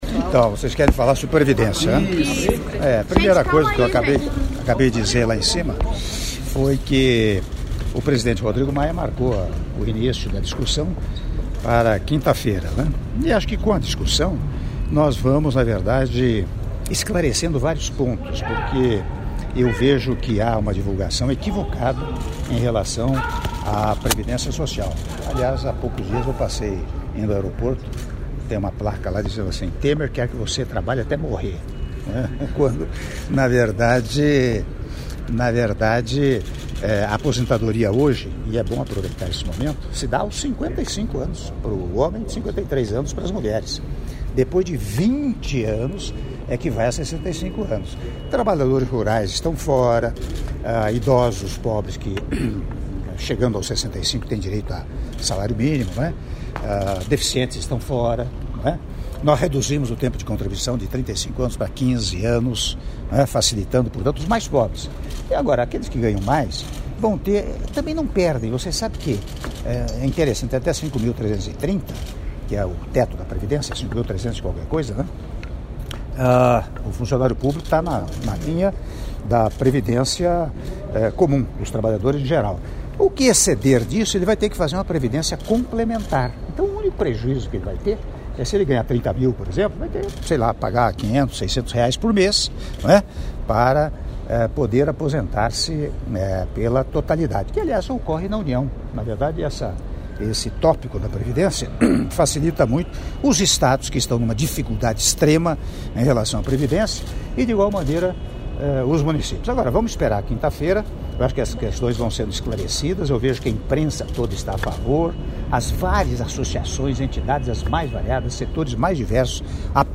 Áudio da entrevista coletiva concedida pelo Presidente da República, Michel Temer, após almoço em homenagem ao senhor Gjorge Ivanov, Presidente da República da Macedônia - Brasília/DF